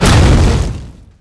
mega_bouncehard4.wav